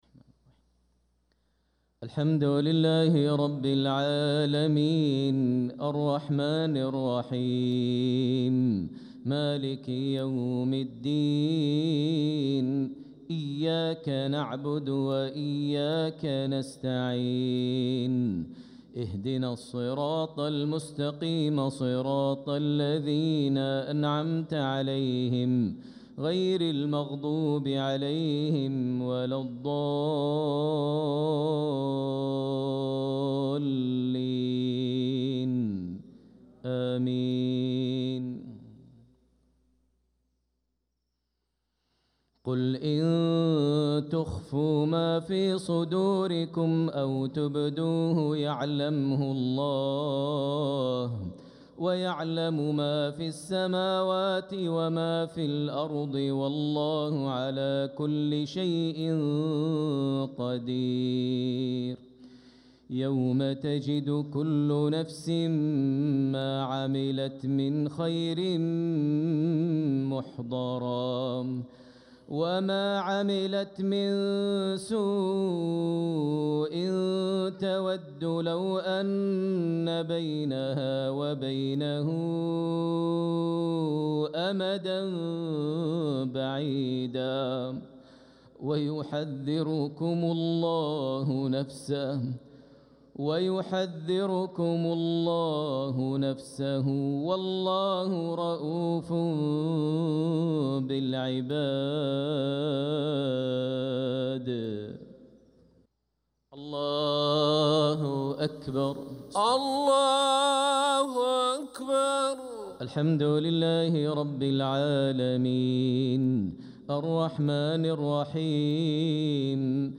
صلاة المغرب للقارئ ماهر المعيقلي 24 ربيع الأول 1446 هـ
تِلَاوَات الْحَرَمَيْن .